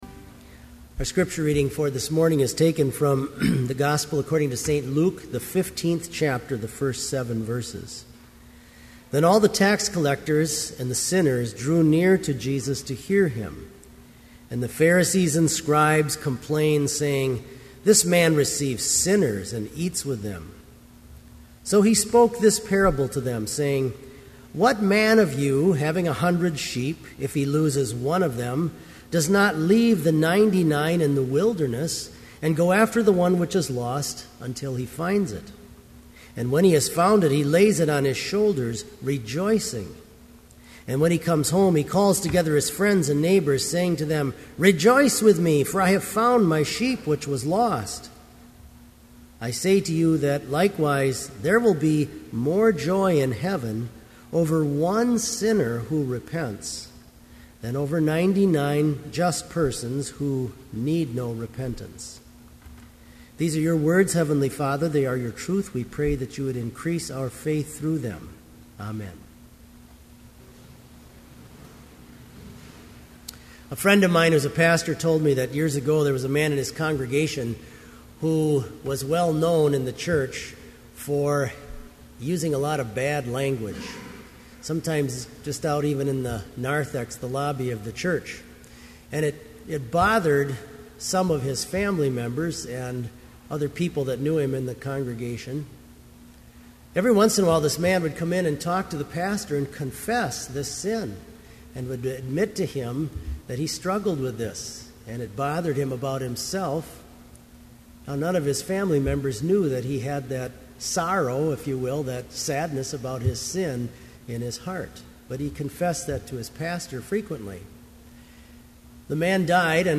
Complete service audio for Summer Chapel - June 27, 2012